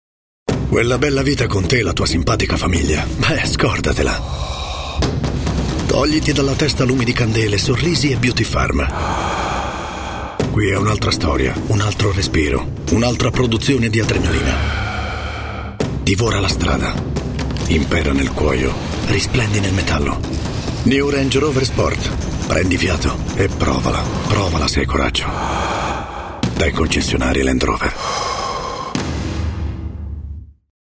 attore doppiatore